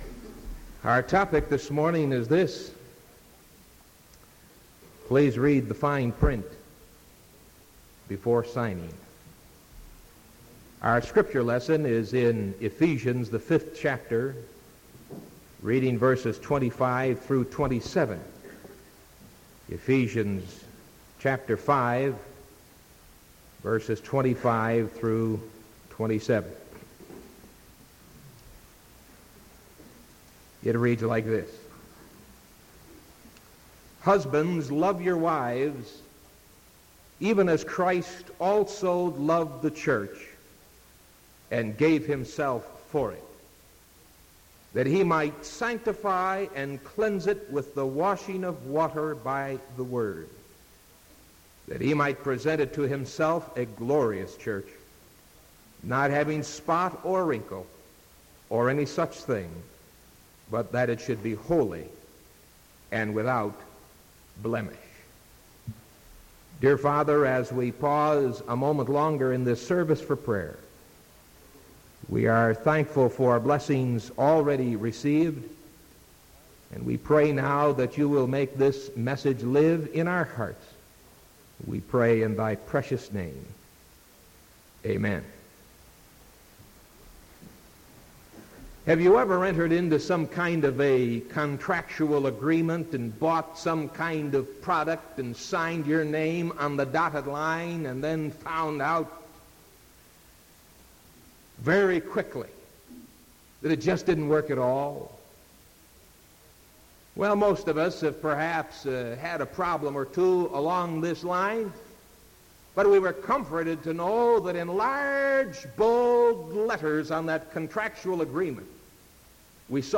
Sermon February 2nd 1975 AM